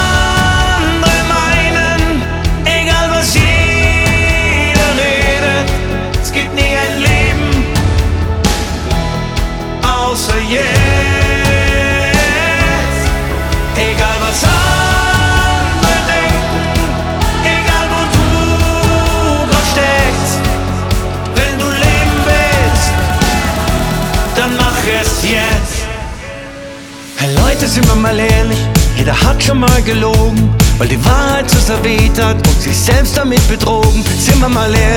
German Pop
Жанр: Поп музыка Длительность